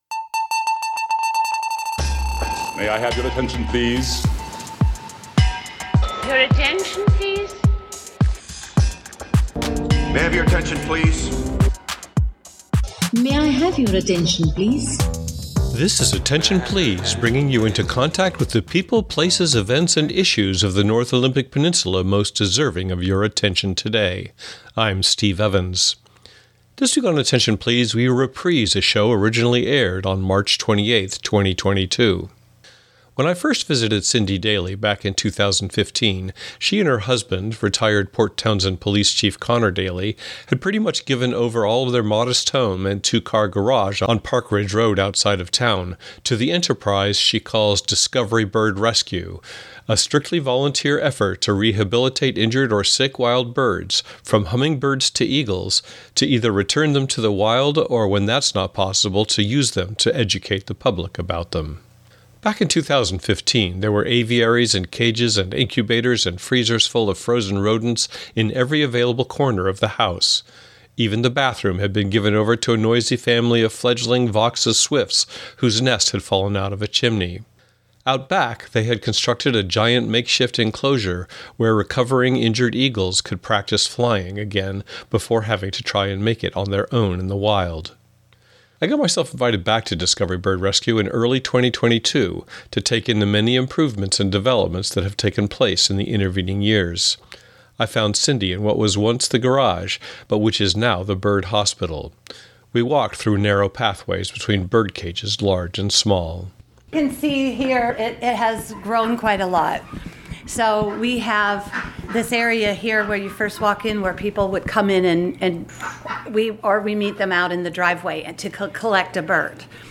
Genre: Radio News.